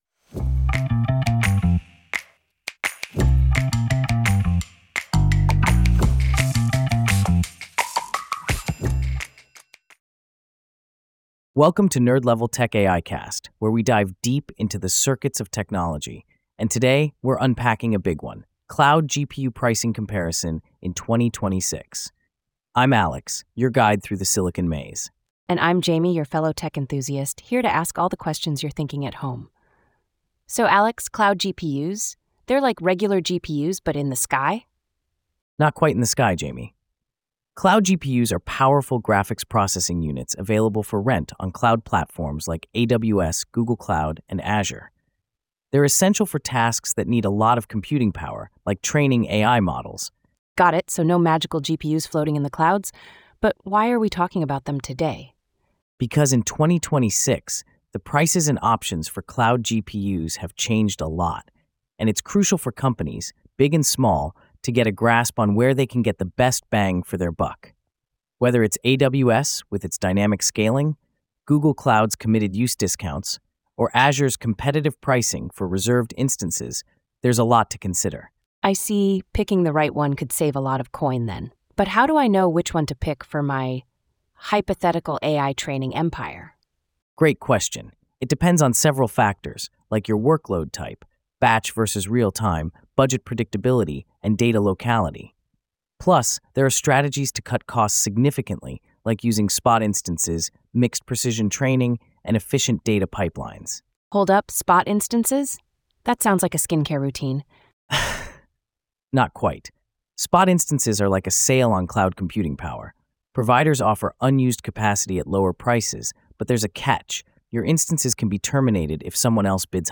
# ai-generated